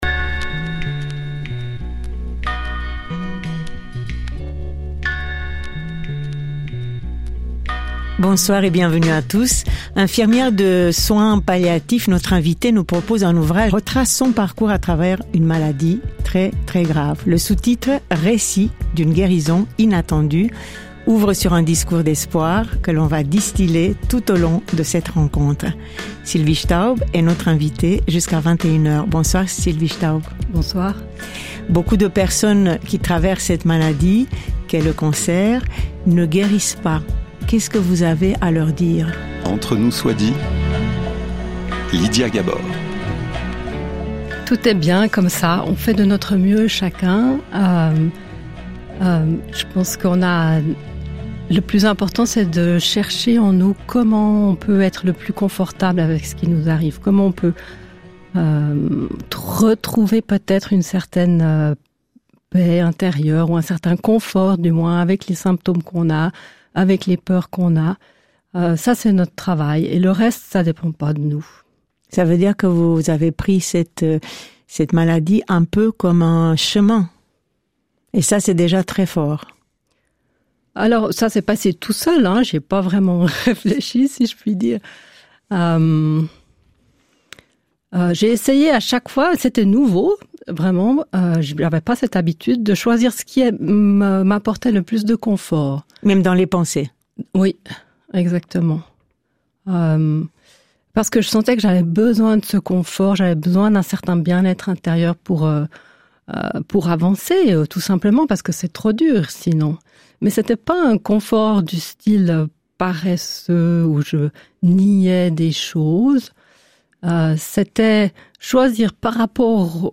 Un moment fort avec sa belle voix, son énergie joyeuse et son sourire.